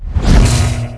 metabolize_fire_reverse.wav